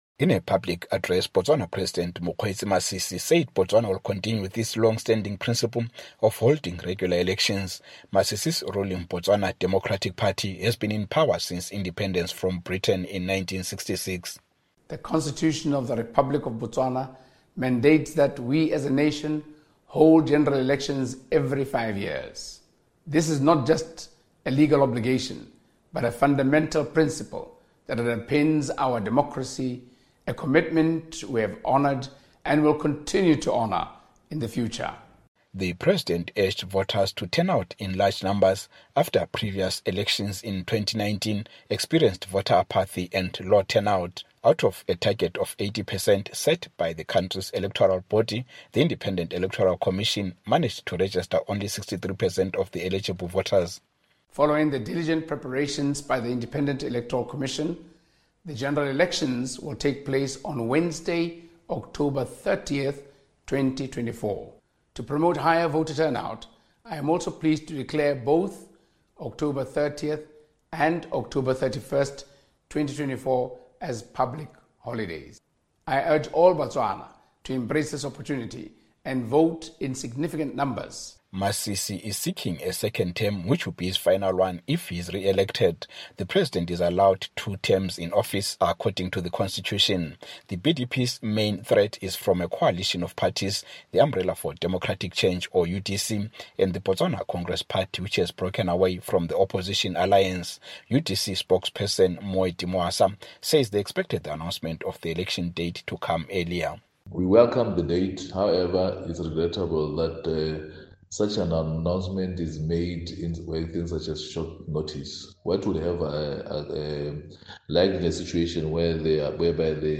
Botswana, Africa's longest democracy, will hold its general election on October 30, President Mokgweetsi Masisi announced Tuesday. Masisi will seek a second and final term after his ruling party endorsed his candidacy over the weekend. From Gaborone